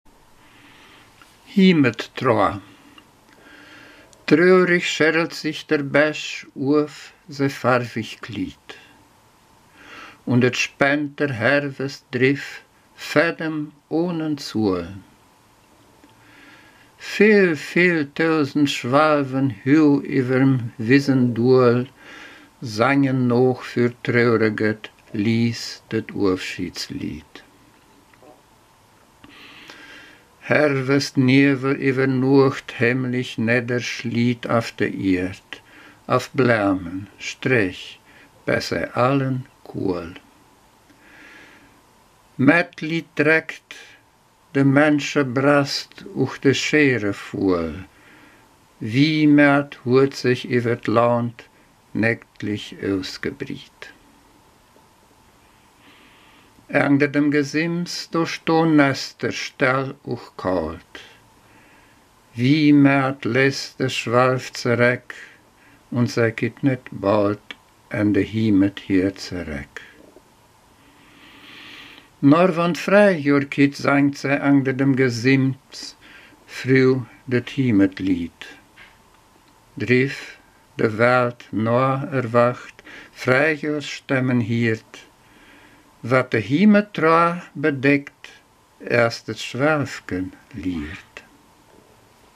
Ortsmundart: Mediasch